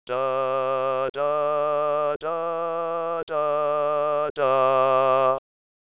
This translator will hopefully make creating songs with the Moonbase Alpha Text to Speech much easier!